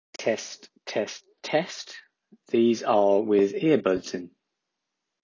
This one is with Apple Earbuds connected and shows you the kind of sound you get if you are a little too far away. Its ok but not great